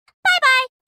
Звуки бай-бай
Женский голос: пока-пока